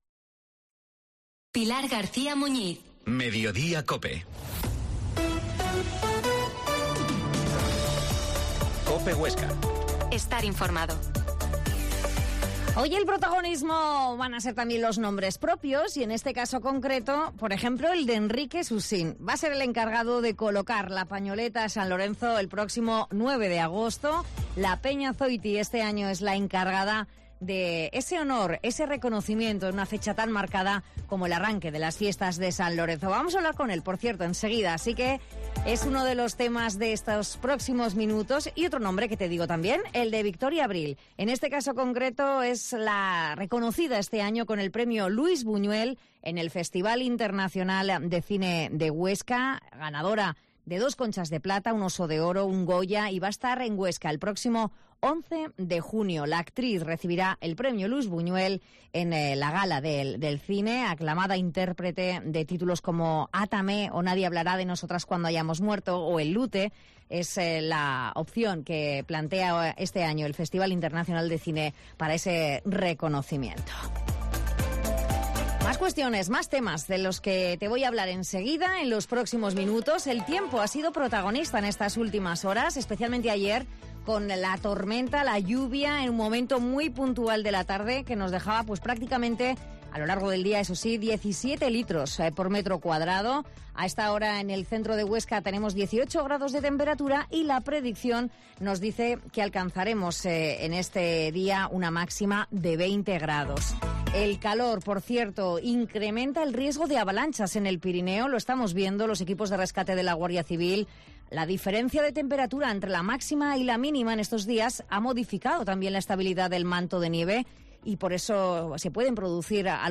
AUDIO: Actualidad local. Entrevista